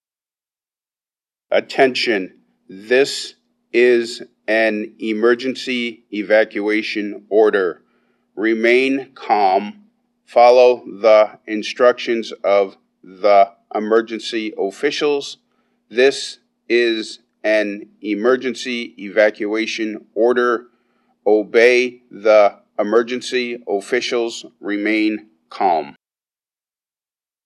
The alert tones are very loud and distinct and should be heard by anyone who is outdoors on the main Tallahassee campus. The alert tone may/may not be followed by voice instructions.
Examples of the warning alert sounds and voice instructions which may be played: